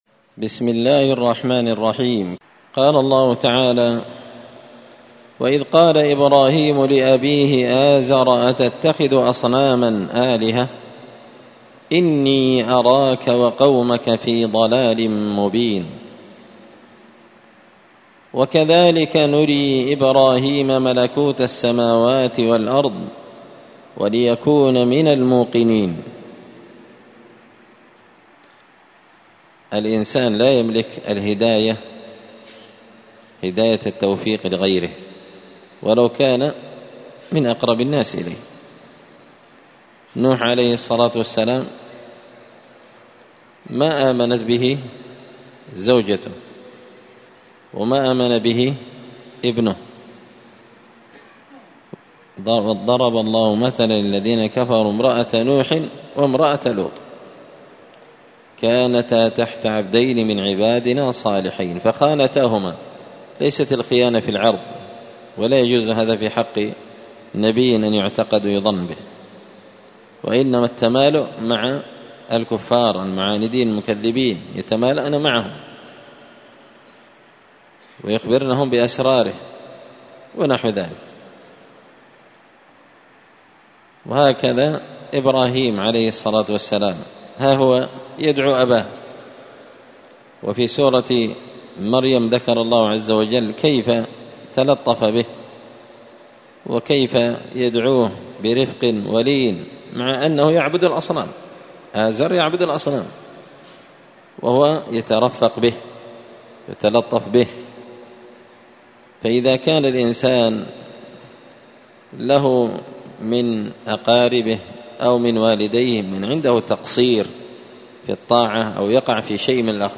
مسجد الفرقان قشن_المهرة_اليمن
مختصر تفسير الإمام البغوي رحمه الله الدرس 323